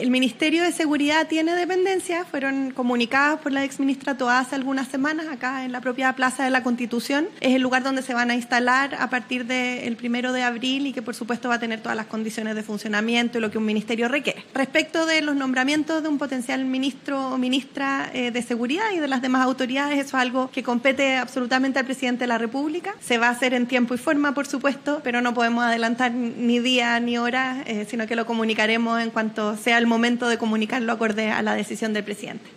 En su habitual punto de prensa de los días lunes, la ministra vocera (s) de Gobierno y de Ciencias, Aisén Etcheverry, se refirió a la pronta instalación del Ministerio de Seguridad Pública, confirmando que su funcionamiento comenzará el 1 de abril en dependencias previamente definidas.